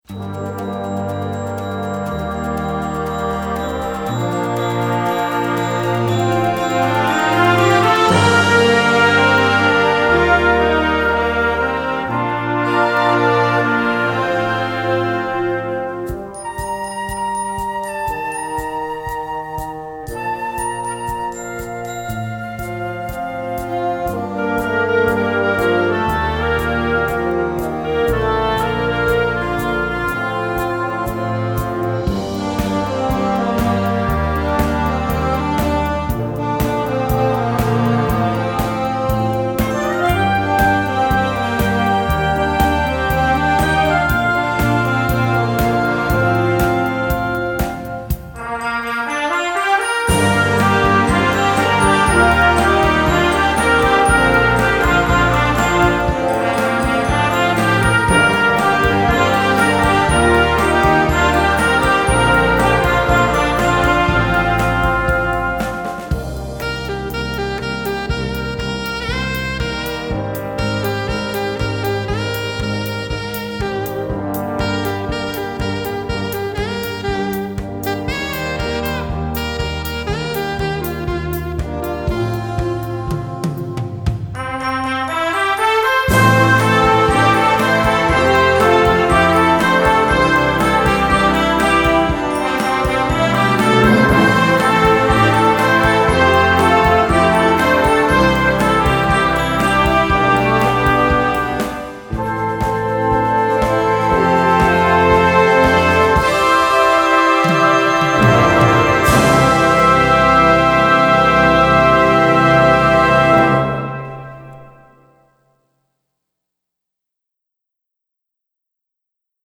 Category: POP & ROCK TUNES Grade 2.0
rock anthem